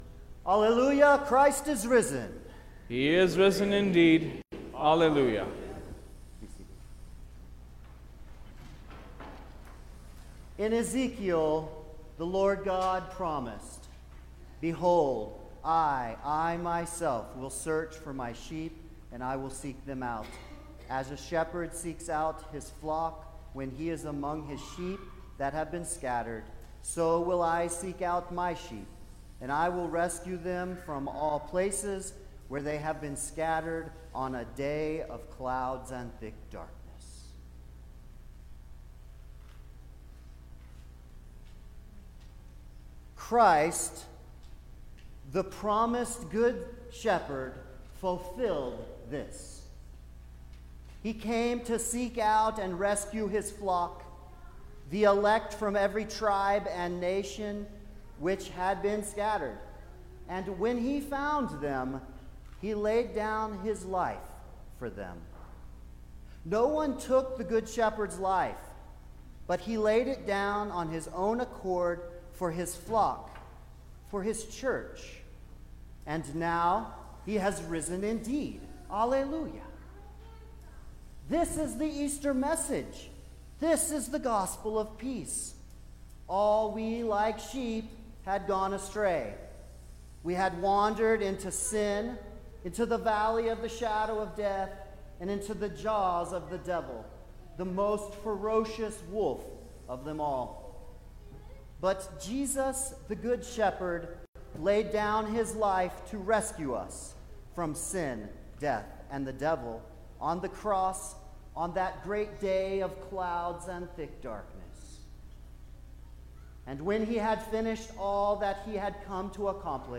May-1_2022_The-Third-Sunday-of-Easter_Sermon-Stereo.mp3